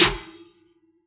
TM88 RachetSnare.wav